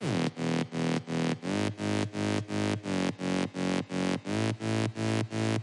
Rave stab 160 BPM F Sharp Major
描述：此声音是使用Access Virus Ti2创建的，并使用第三方效果和处理器进行处理。
声道立体声